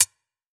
UHH_ElectroHatD_Hit-05.wav